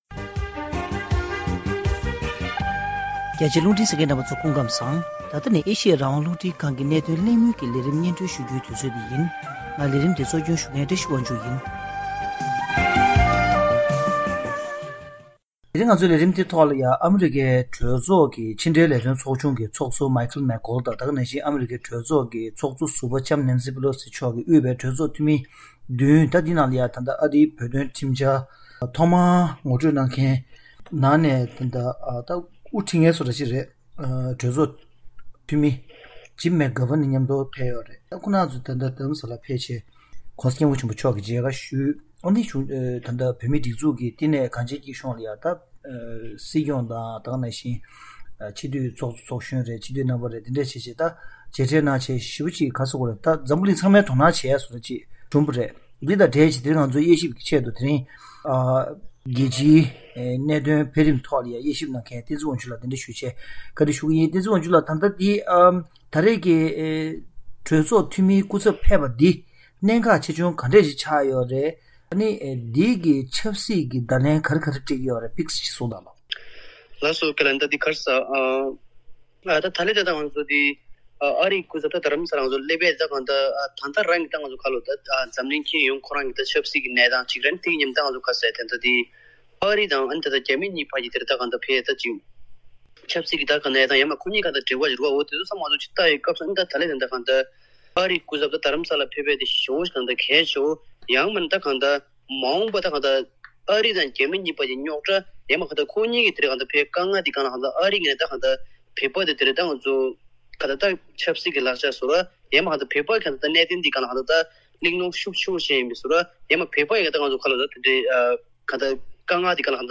གཤམ་ལ་གནད་དོན་གླེང་མོལ་གྱི་ལས་རིམ་ནང་།